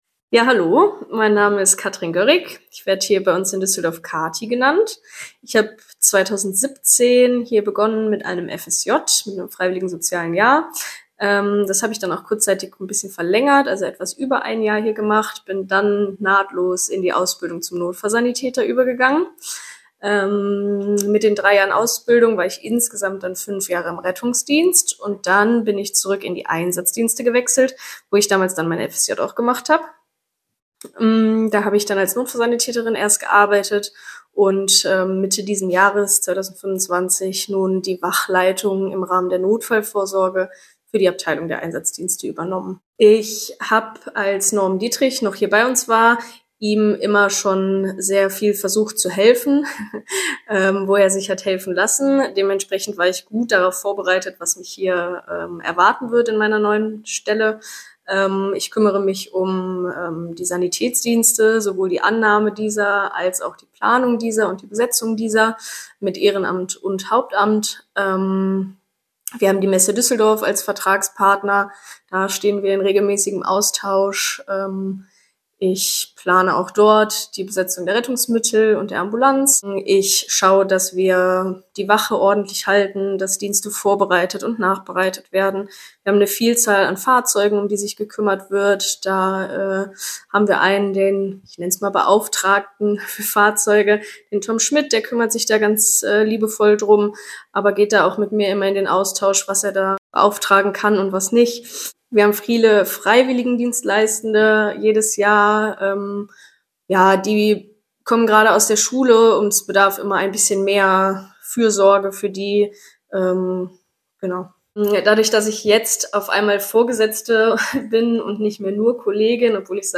In unserer Audioausstellung erzählen Mitarbeitende und Ehrenamtliche von ihrem Engagement bei den Maltesern in Düsseldorf.